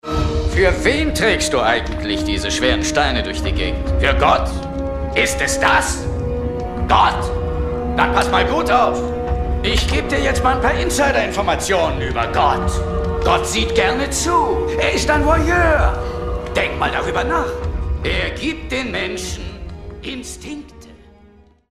Frank Glaubrecht ~ Synchronsprecher
Ob in markant-seriösem oder männlich-sinnlichem Ton, Frank Glaubrecht leiht sie den größten Stars des Hollywoodfilms.
Frank_Glaubrecht_Al_Pacino.mp3